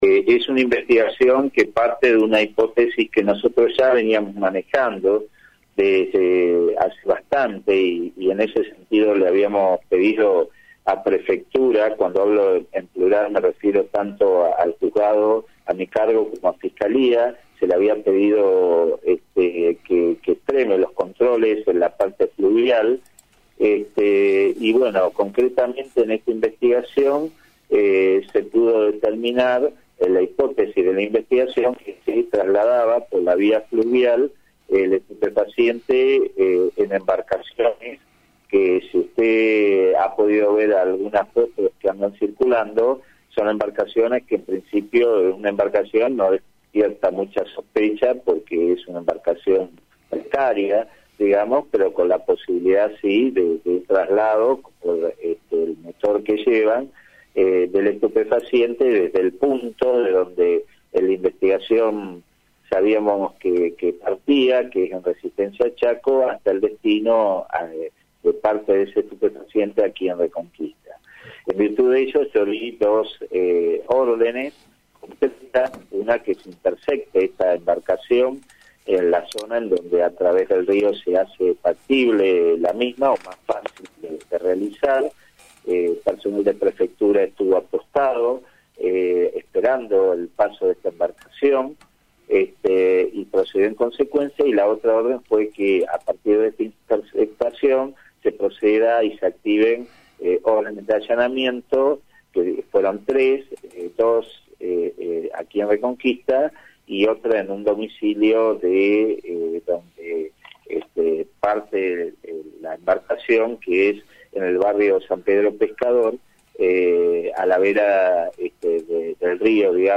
El juez federal de Reconquista, doctor Aldo Alluralde confirmó en Radio Amanecer los procedimientos llevados adelante el día jueves y dio detalles de la investigación.
JUEZ-FEDERAL-ALDO-ALURRALDE.mp3